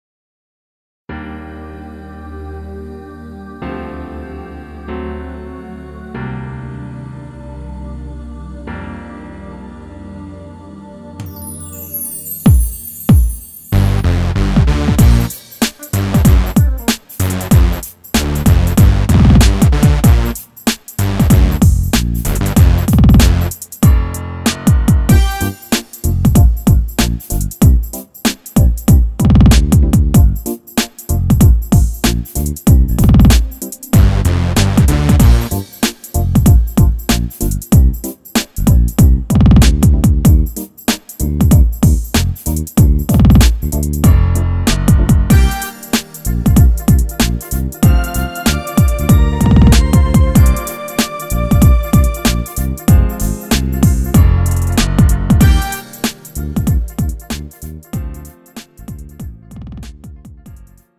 음정 (-2)
장르 가요 구분 Lite MR